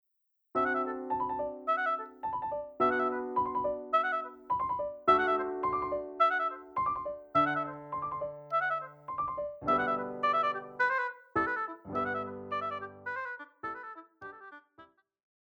古典
雙簧管
鋼琴
鋼琴曲
獨奏與伴奏
有節拍器
Allegretto scherzando, arranged for oboe and piano.